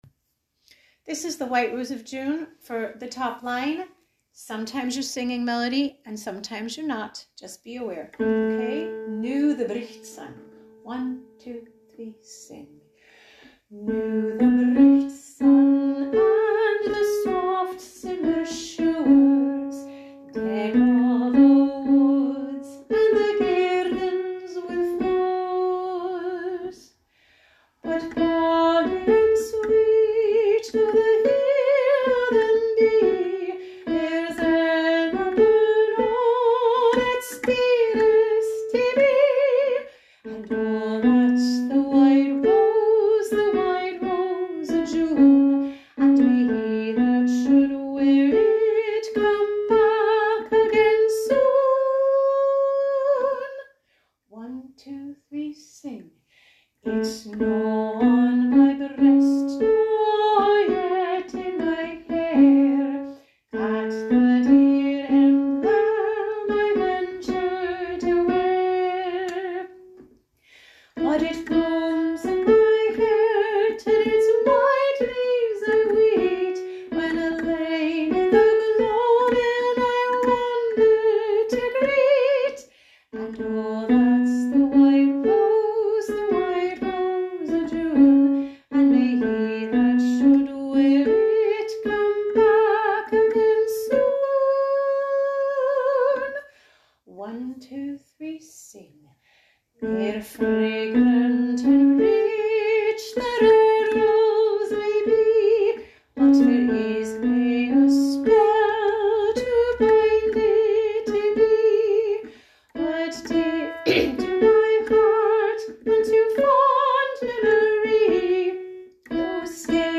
Sing-alongs (mp3):High VoiceMiddle VoiceLow Voice
white-rose-of-june-high.mp3